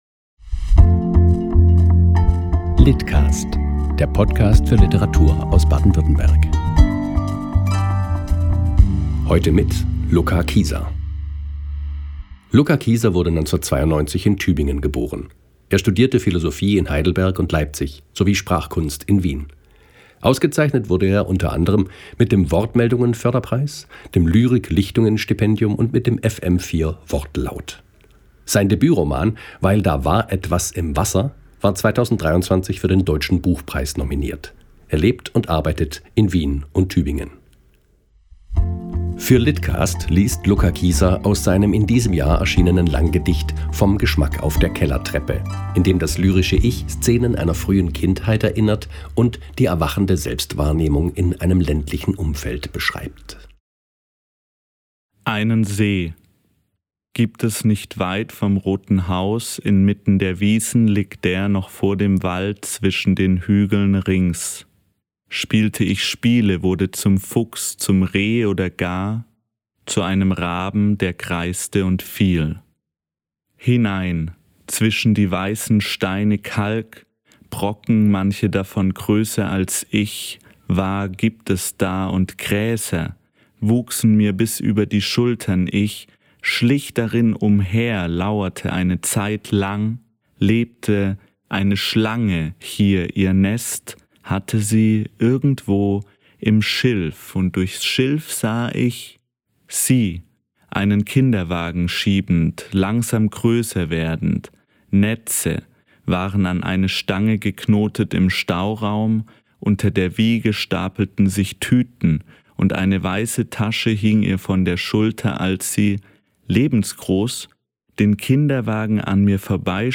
liest aus dem Langgedicht „vom Geschmack auf der Kellertreppe“